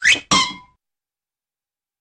Звуки поскальзывания
Поскользнулся, упал